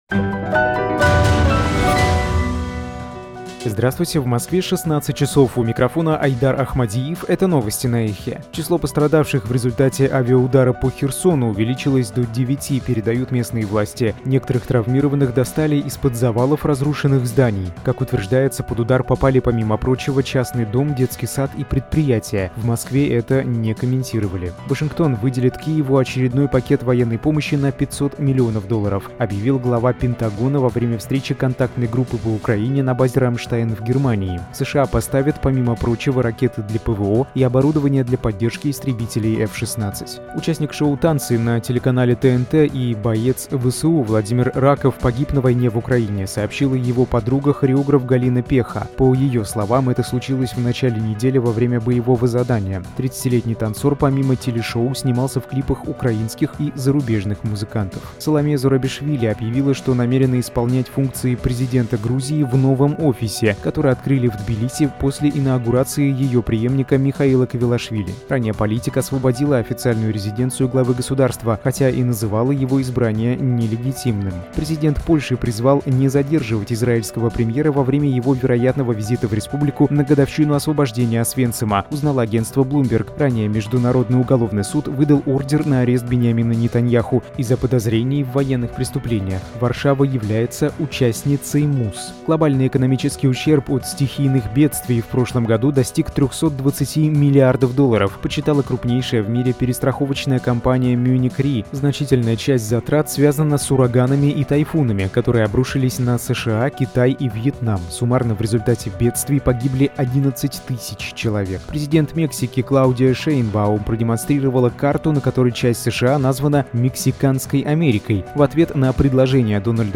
Слушайте свежий выпуск новостей «Эха».
Новости 16:00